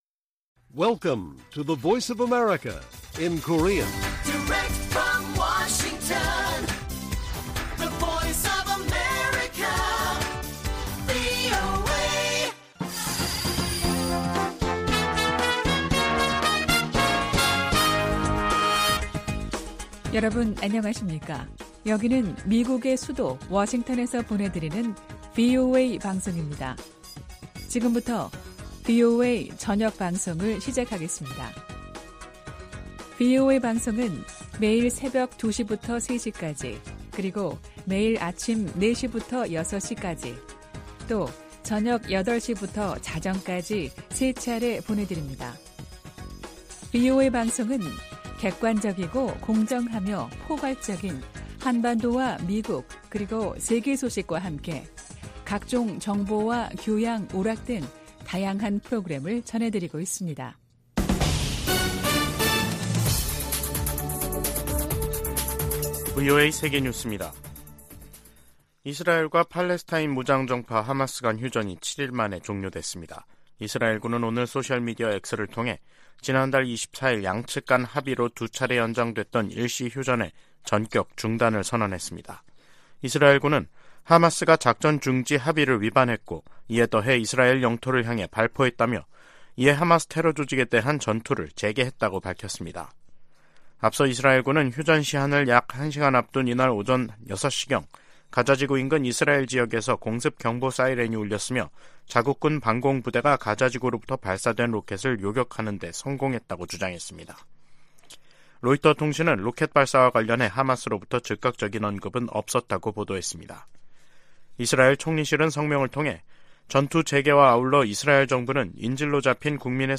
VOA 한국어 간판 뉴스 프로그램 '뉴스 투데이', 2023년 12월 1일 1부 방송입니다. 미국 정부가 북한의 군사 정찰위성 발사에 대한 대응 조치로 북한 국적자 8명과 기관 1곳을 전격 제재했습니다. 한국 정부가 북한의 정찰위성 개발 등에 관련한 북한 사람들에 독자 제재를 발표했습니다. 북한이 유엔 안보리를 선전 도구로 이용하고 있다고 유엔 주재 미국대표부가 비판했습니다.